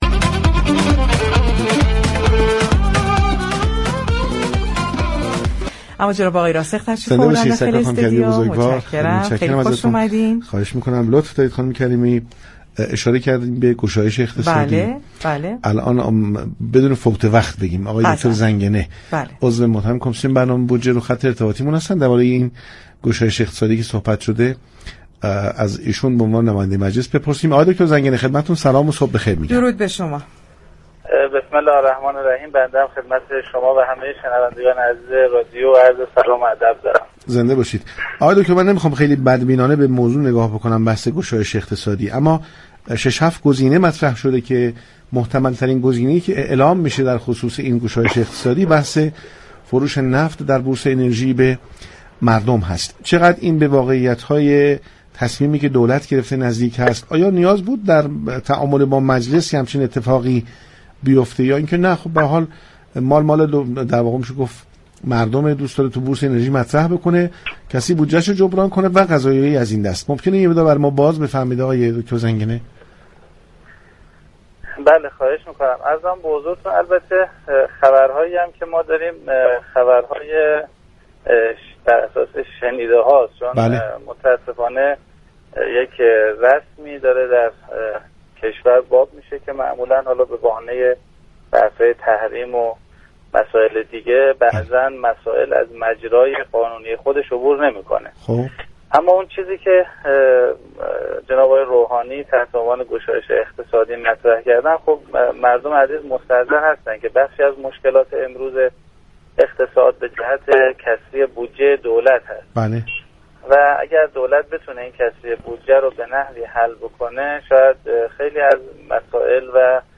دكتر محسن زنگنه، عضو كمیسیون برنامه و بودجه در خصوص گشایش اقتصادی كه چندی پیش رئیس جمهور نوید آن را به مردم دادند با پارك شهر رادیو تهران گفتگو كرد.